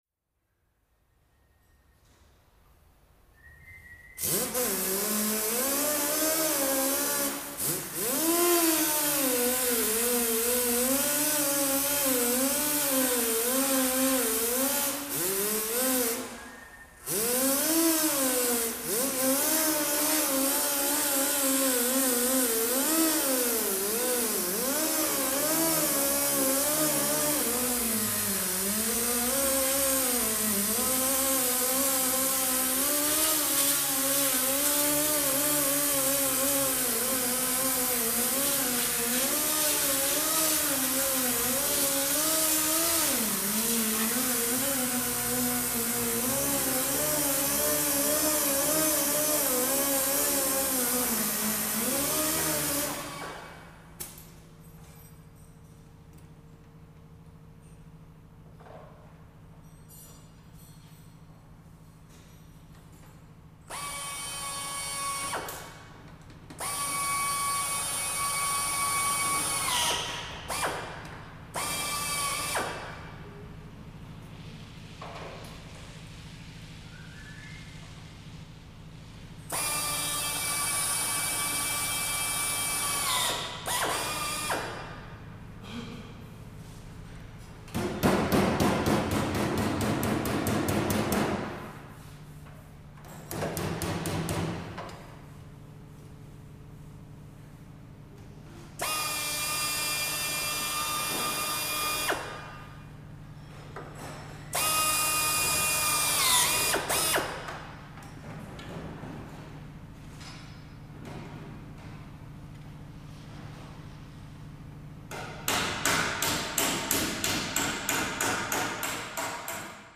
Auto Shop
Automobile Body Shop Ambience, W Pneumatic Tools, Hammering Dents, Telephone Ring, Light Voices.